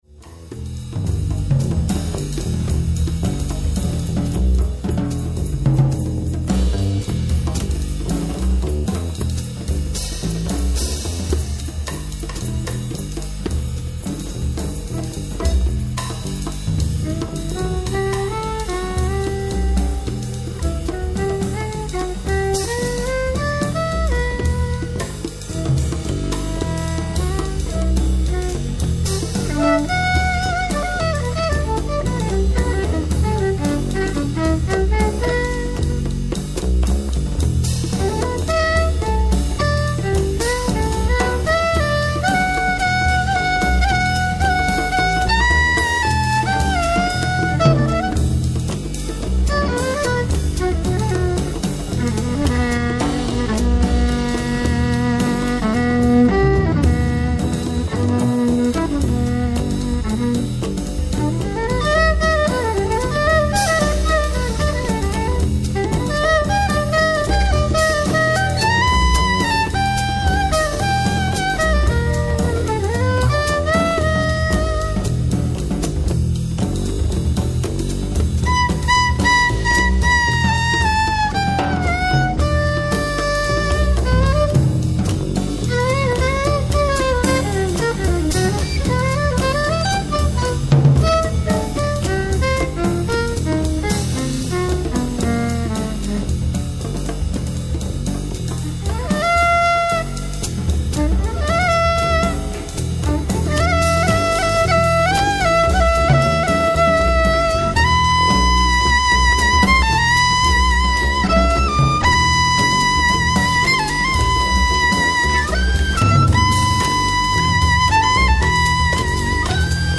baritone saxofone, tarogato
violin, percussion, flutes
double bass
drum set